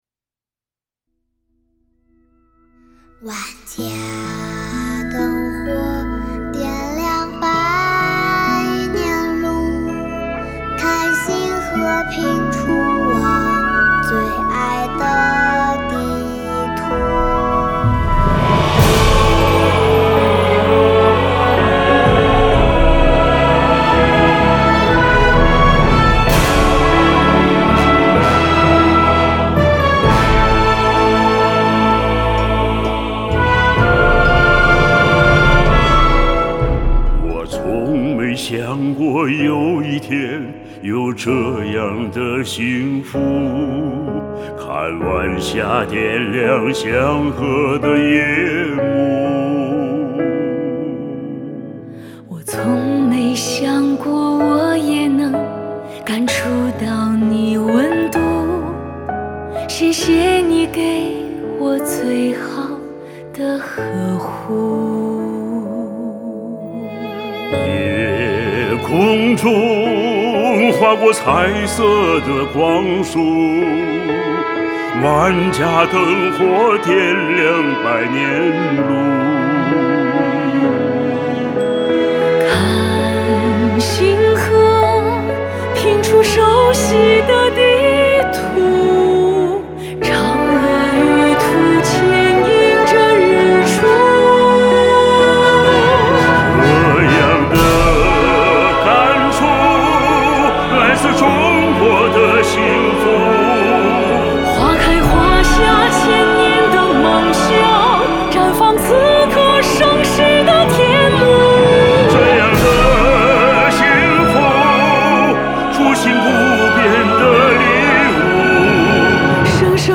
歌曲旋律刚柔并济，替前辈唱出了心底的愿望，也为自己唱出了中国新篇章的精彩。
弦乐
铜管
木管
童声
合唱